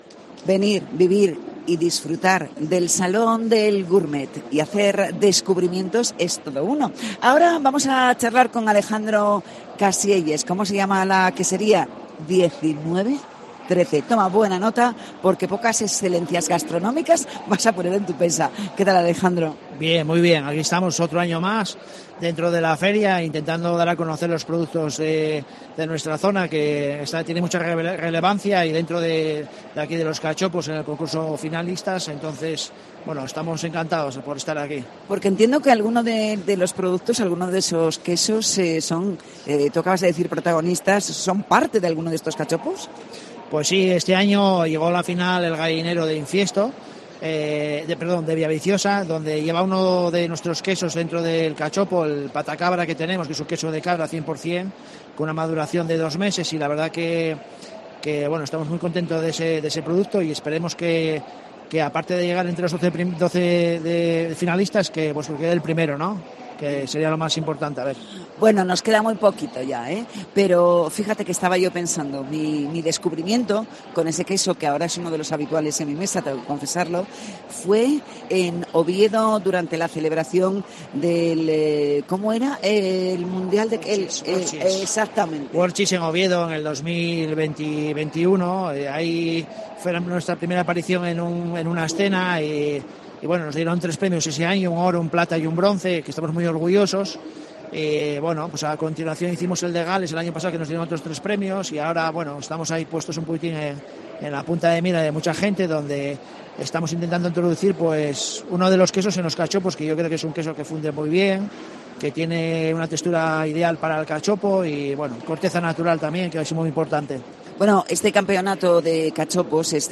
Salón Gourmets 2023: entrevista